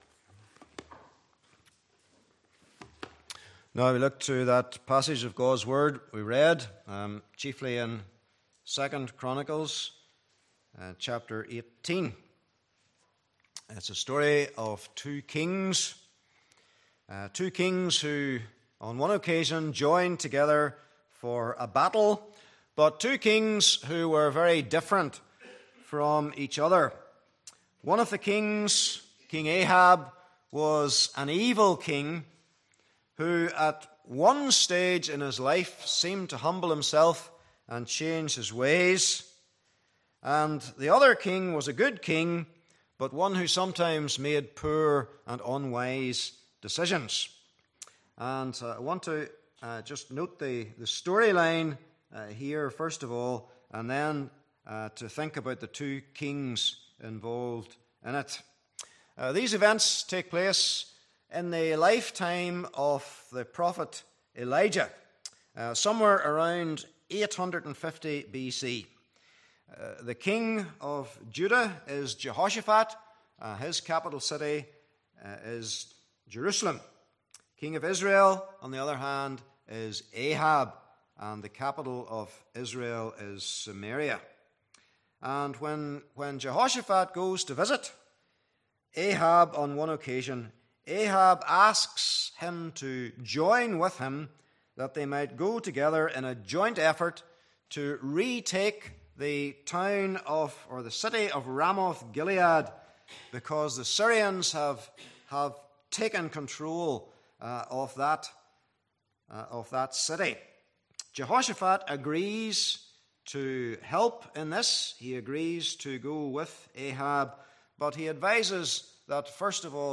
Passage: 2 Chronicles 18:1-19:3 Service Type: Evening Service